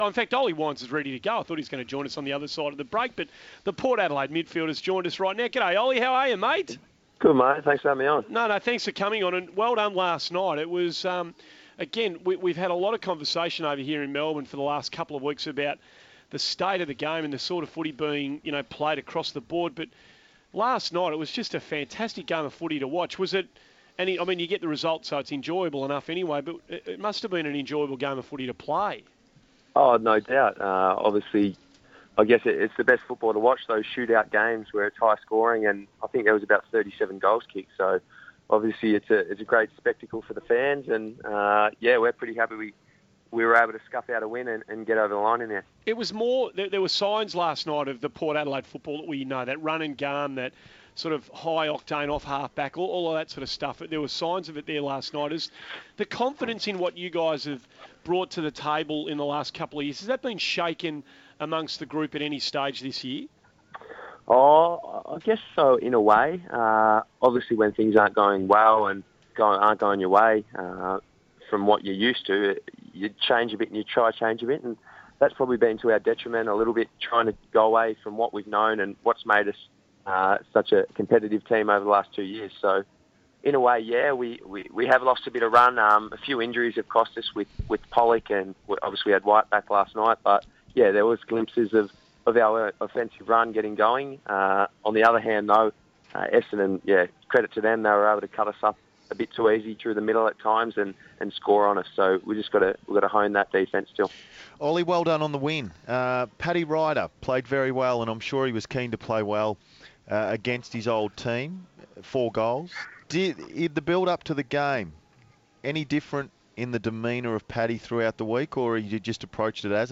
Port Adelaide midfielder Ollie Wines joins our commentary team to discuss Port Adelaide's win over Essendon and the finals chances for his club.